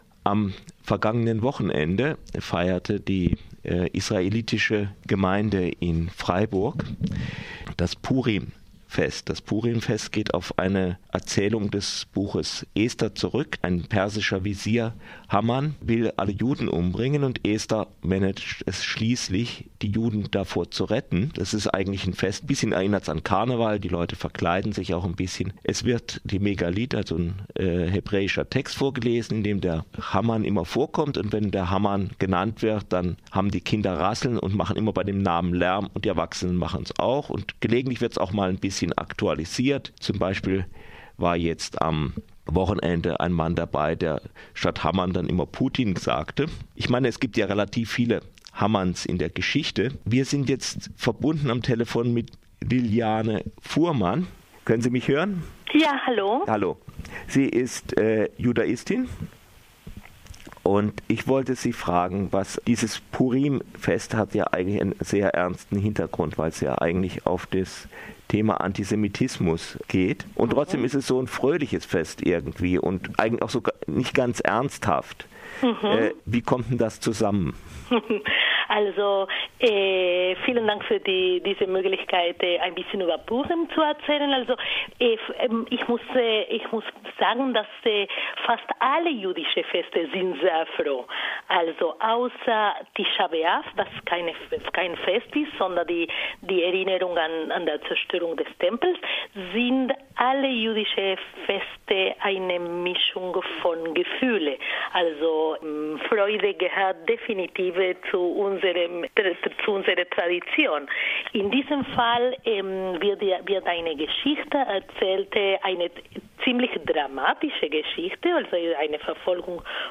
Radio Dreyeckland besuchte die Israelitische Gemeinde in Freiburg beim Purim Fest.